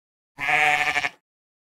38. sheep